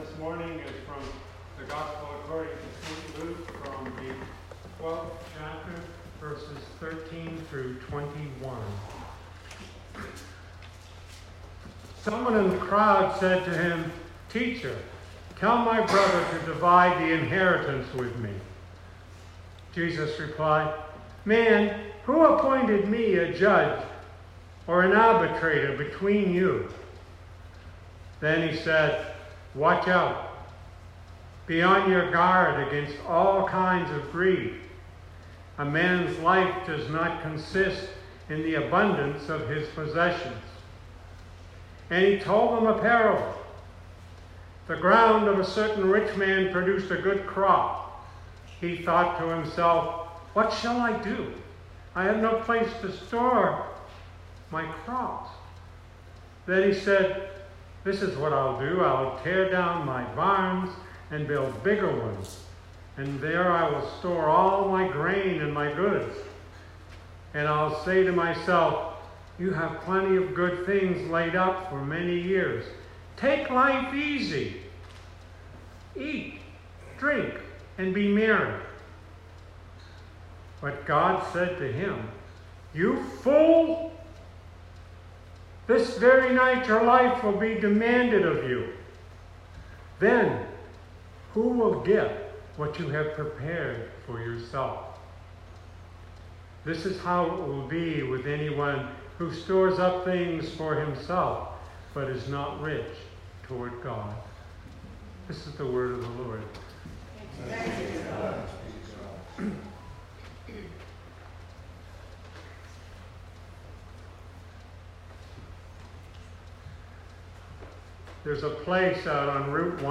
Sermon 2019-08-04